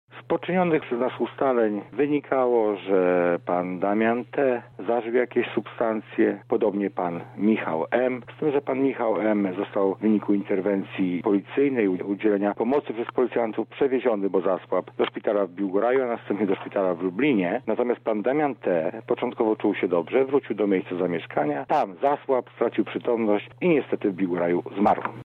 Tylko jednego udało się uratować – tłumaczy Marek Głowala Prokurator Rejonowy w Biłgoraja